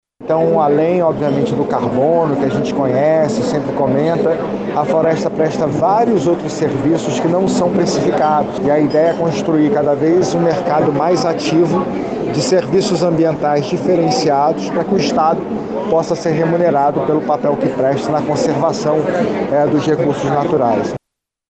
Os créditos anunciados foram aprovados pelo Governo Federal a partir de metas voltadas a redução do desmatamento que o Amazonas alcançou de 2006 a 2015. Como destaca o secretário de Meio Ambiente do Estado, Eduardo Taveira.
Sonora-2-Eduardo-Taveira-secretario-de-Estado-do-Meio-Ambiente.mp3